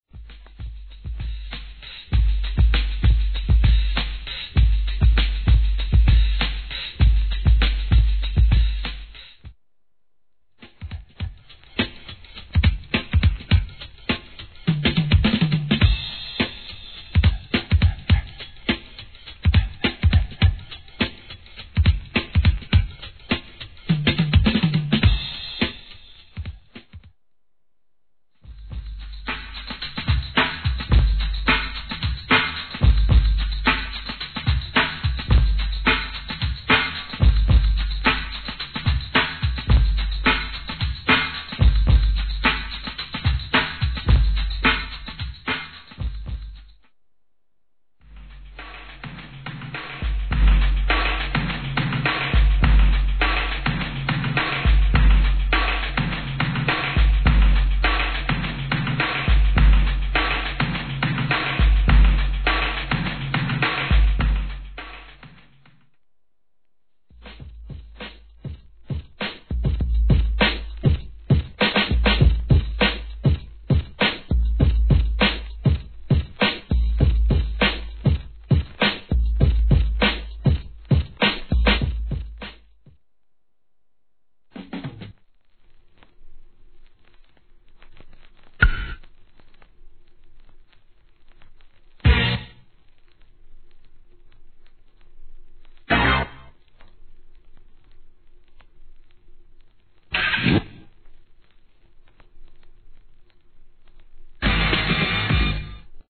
HIP HOP/R&B
沢山の'80s効果音、声ネタ収録で重宝します!!